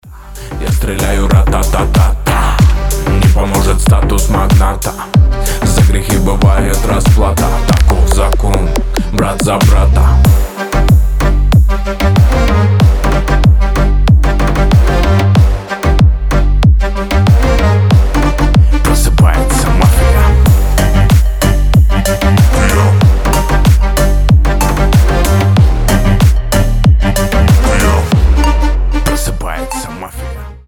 клубные , рэп , пацанские
басы , в машину , на друга , качающие , восточные , g-house